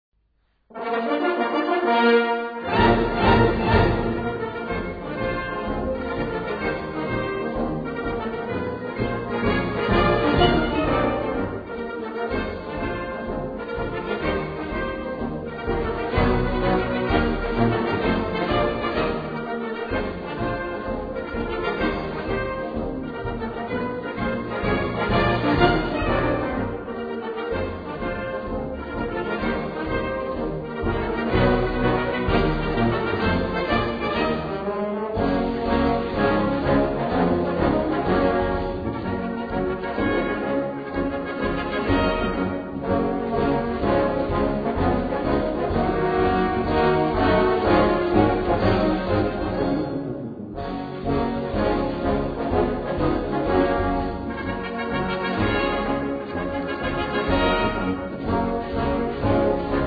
Gattung: Marsch
Besetzung: Blasorchester